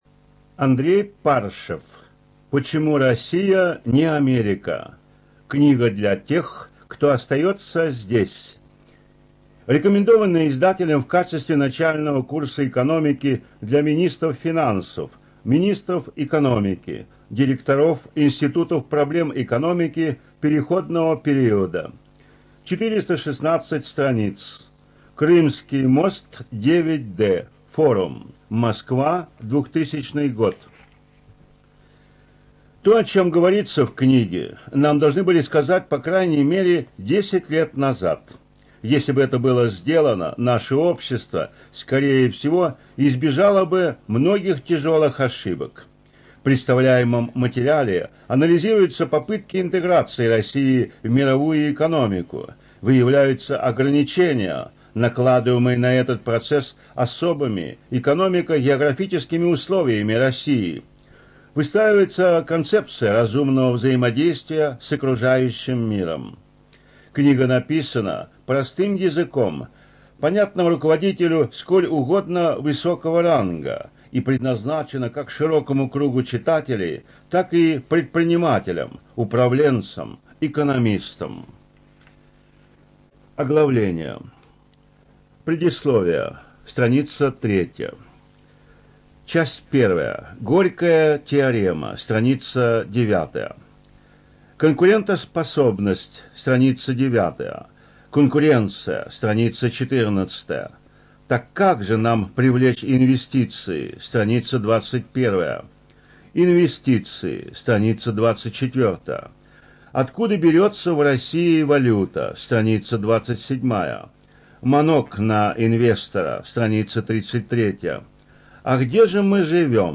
ЖанрПублицистика
Студия звукозаписиРоссийская государственная библиотека для слепых